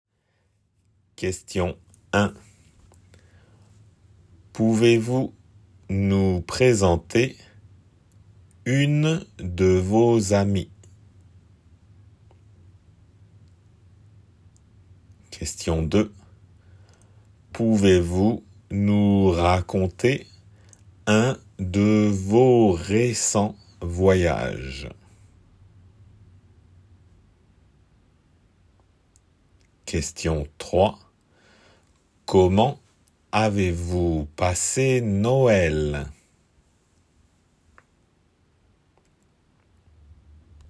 questions